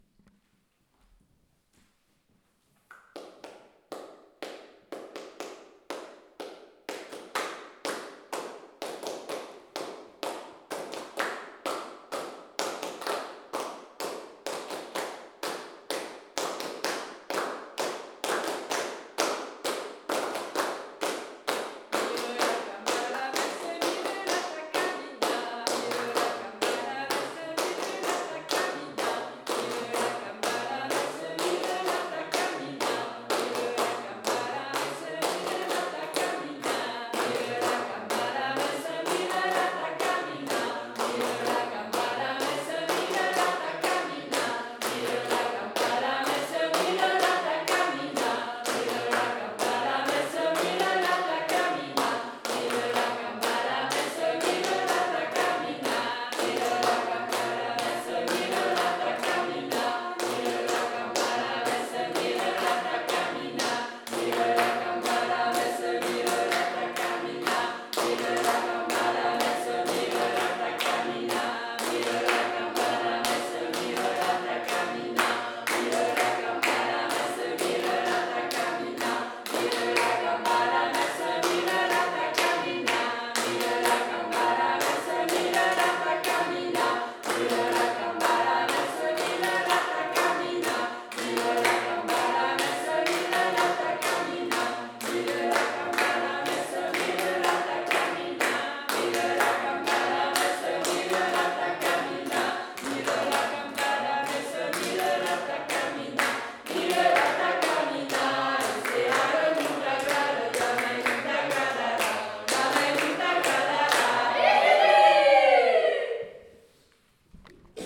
Lieu : Toulouse
Genre : chant
Type de voix : voix mixtes
Production du son : chanté
Descripteurs : polyphonie